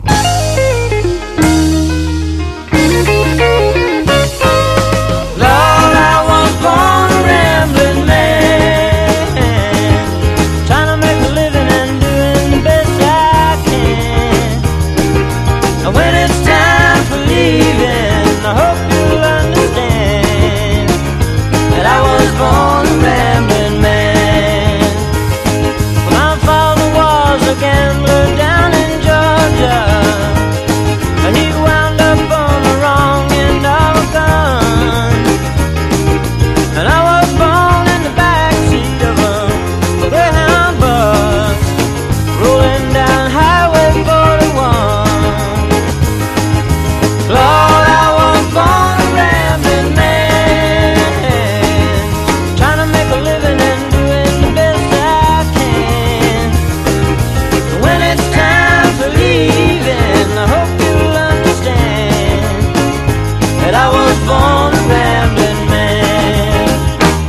ROCK / 70'S / SOUTHERN ROCK